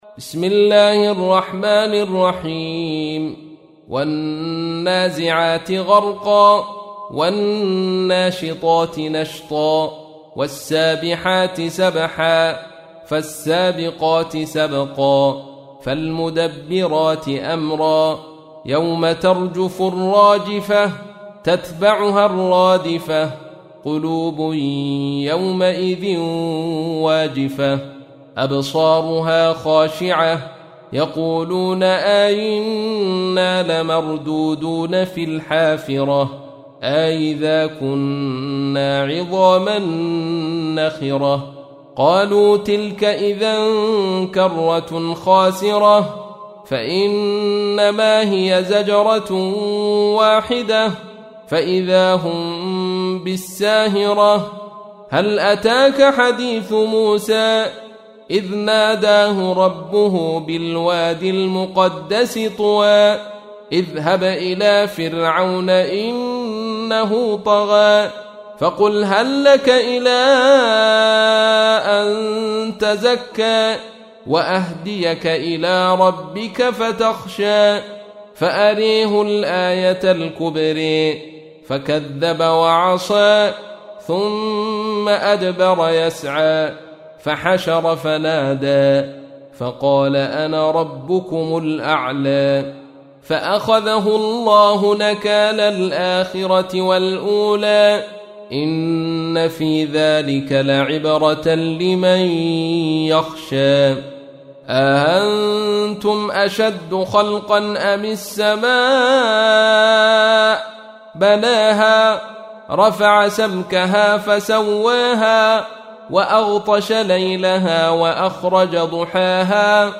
تحميل : 79. سورة النازعات / القارئ عبد الرشيد صوفي / القرآن الكريم / موقع يا حسين